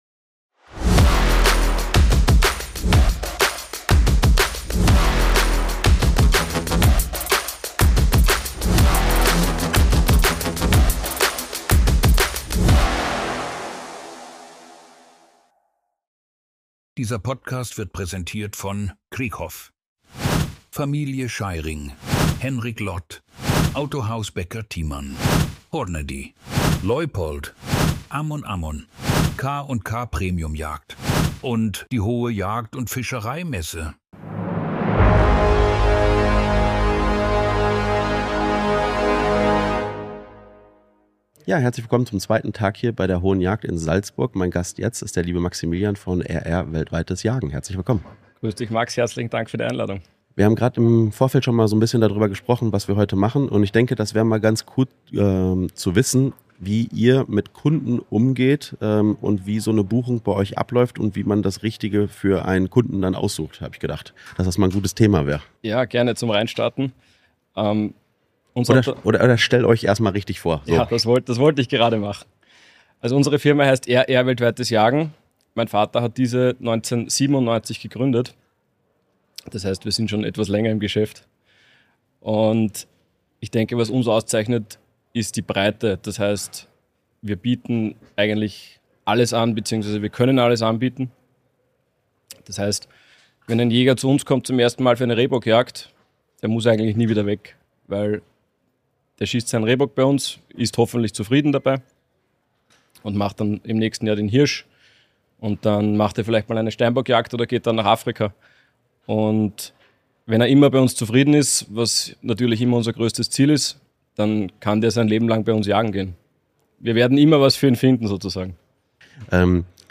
Aufgenommen wurde die Episode auf der Hohe Jagd & Fischerei Messe Salzburg.